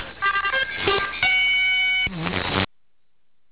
The Microphonium is a RCA 44BX.